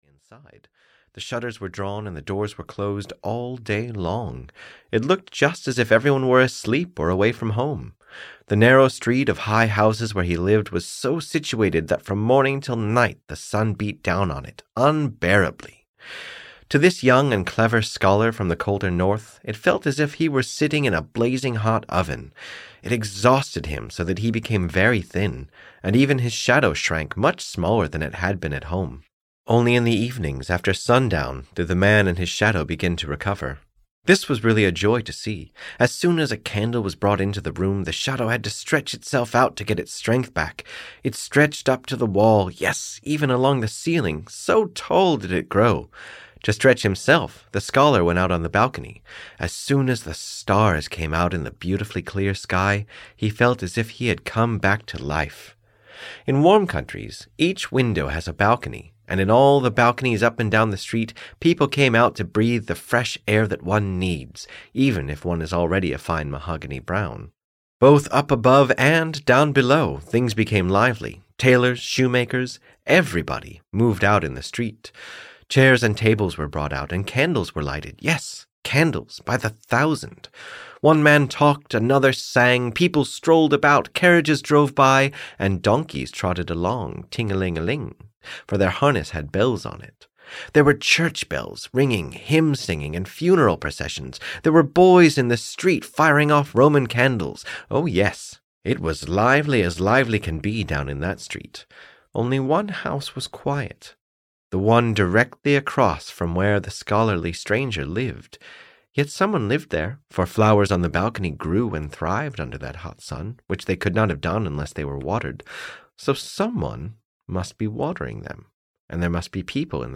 The Shadow (EN) audiokniha
Ukázka z knihy